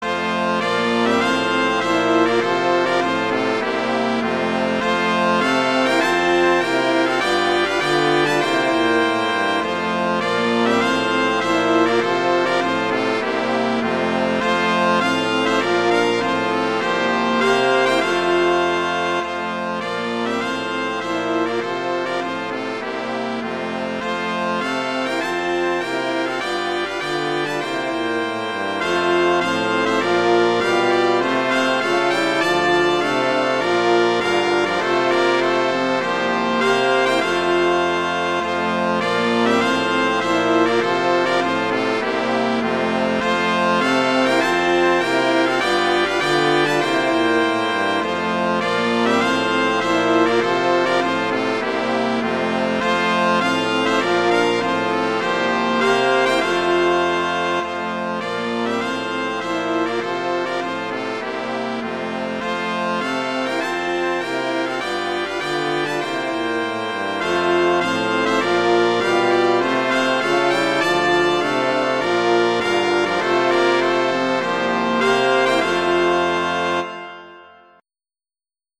arrangements for brass quintet